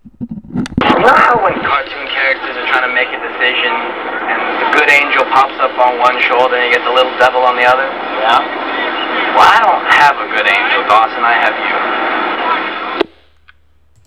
Using a tape recorder and my computer's sound programs, I was able to create WAV files of clips from the show.